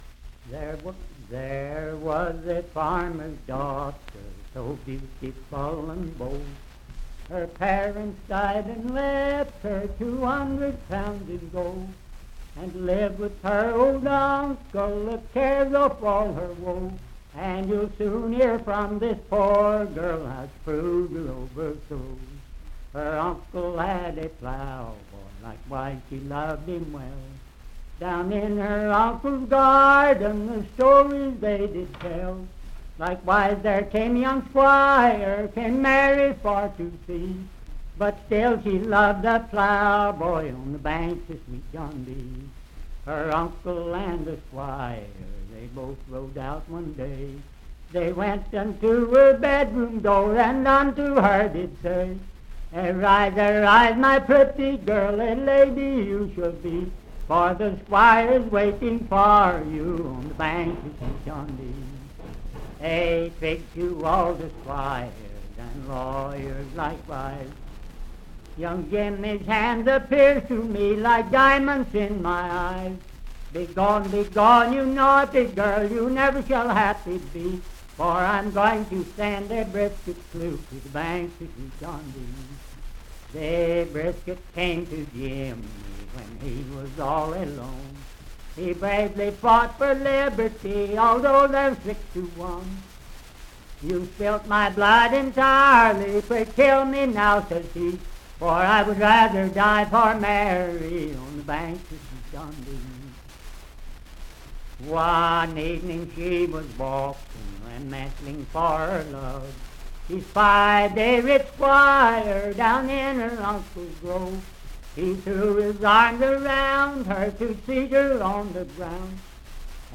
Unaccompanied vocal music
Voice (sung)
Parkersburg (W. Va.), Wood County (W. Va.)